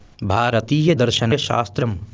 शृणु) (/ˈbhɑːrətjədərʃənʃɑːstrəm/)) (हिन्दी: मीमांसा, आङ्ग्ल: Mīmāṃsā) द्विधा विभागौ भवतः।